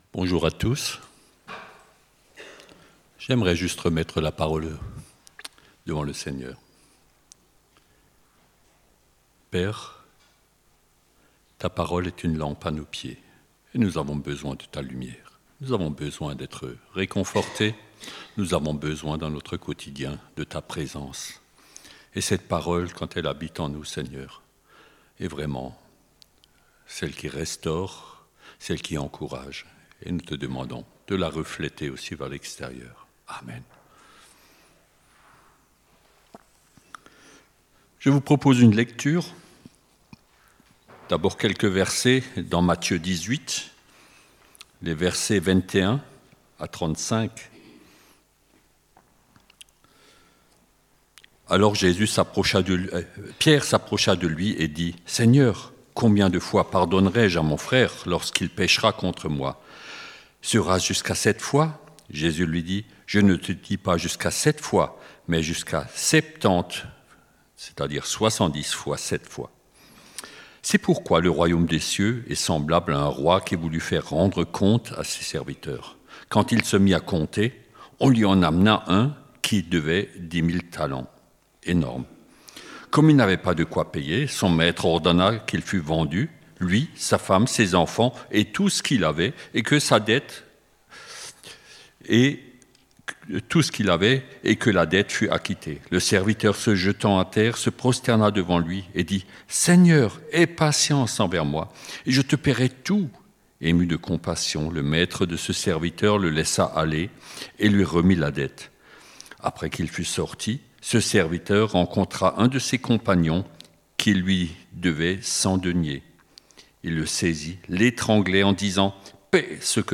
Ecoutez les différents messages de l'église évangélique de Bouxwiller … continue reading 338 episodes # Religion # Christianisme # EEBouxwiller # Culte # Chrétien # Croire En Dieu